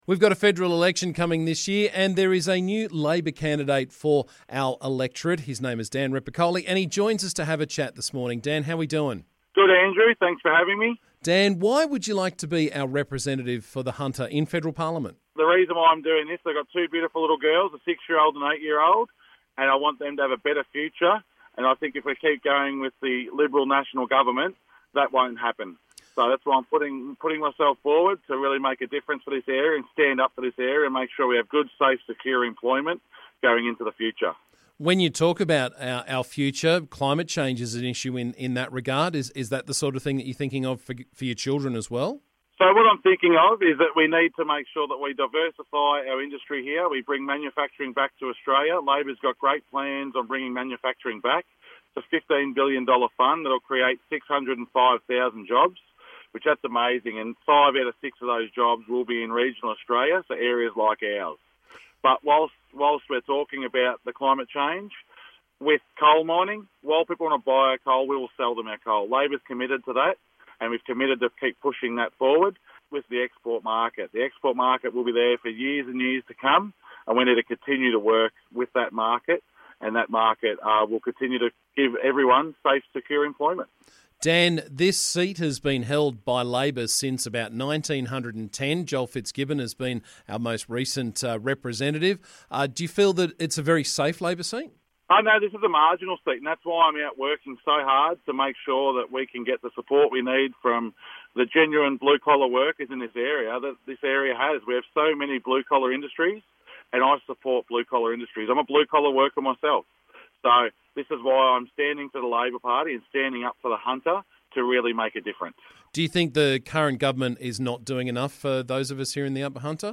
Former Olympian Dan Repacholi will be running in the upcoming Federal as the ALP candidate and he was on the show to tell us why this morning.